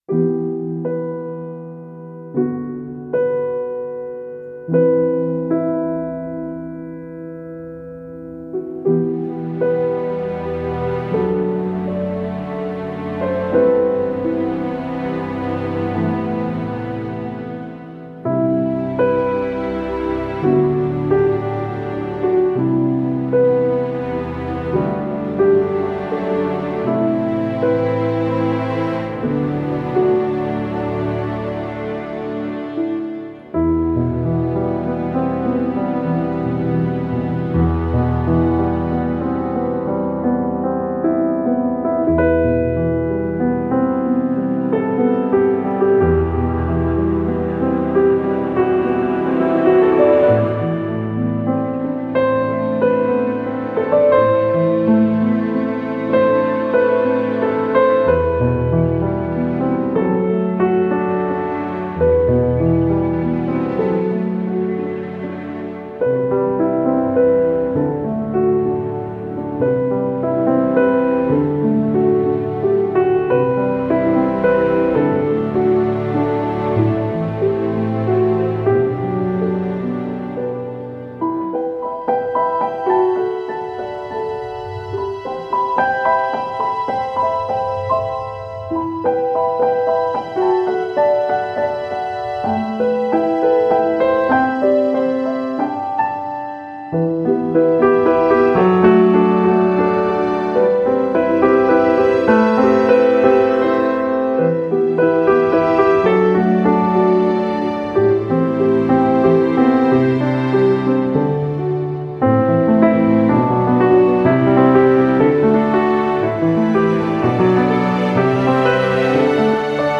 Impact Soundworks Pearl Concert Grand是一款优美的钢琴音色库，采样了一架雅马哈C7三角钢琴，拥有四种麦克风视角，每种都提供了独特而醇厚的声音。
- 雅马哈 C7 音乐厅录制
- 四个麦克风位置：关闭、踏板、舞台、大厅
- 半音键释放和踏板噪音